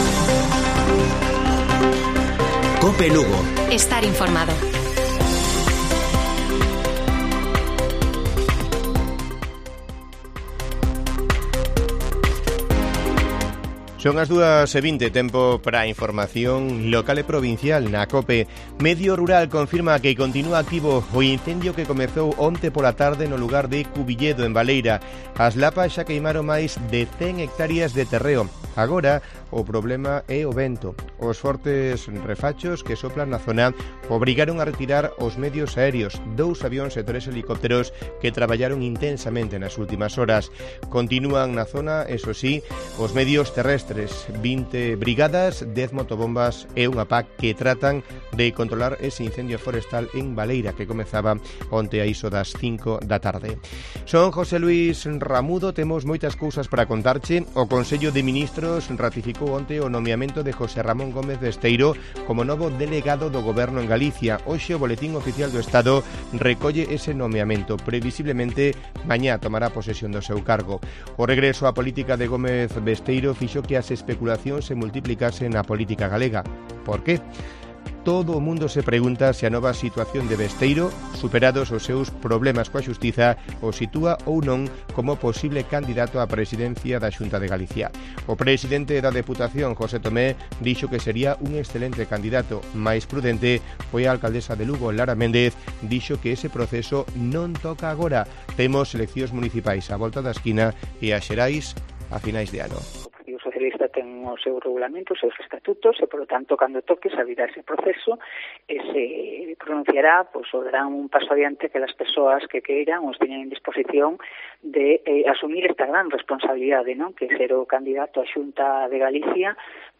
Informativo Mediodía de Cope Lugo. 29 de marzo. 14:20 horas